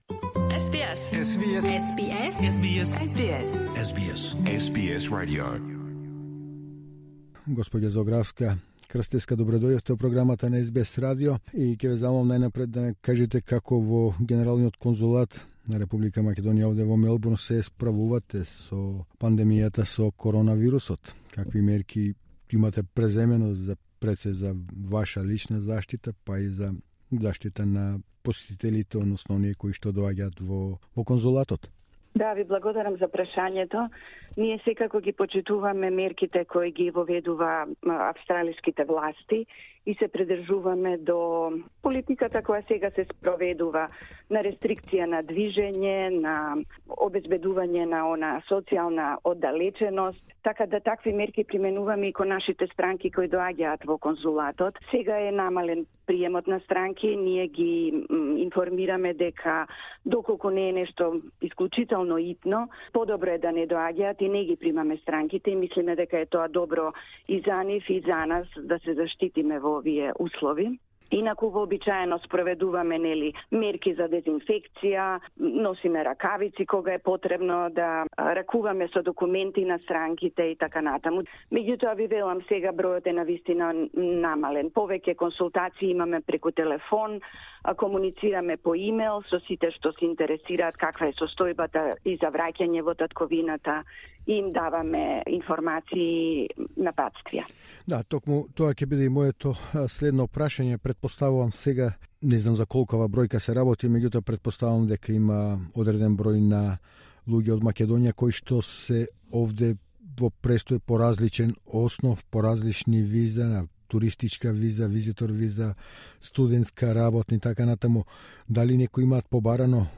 Амбасадата на Република Македонија и Генералниот Конзулат во Мелбурн се во постојан контакт со нив со совети и упатства како да постапат во новонастаните услови. Во тонскиот разговор за СБС на македонски, Сања Зографска-Крстеска, Генерален Конзул на Р.Македонија во Мелбурн објаснува како во вакви услови да постапат државјаните на Македонија кои се во моментов во Австралија.
Ms Sanja Zografska-Krsteska, Macedonian Consul-General in Melbourne at the SBS studio Source: SBS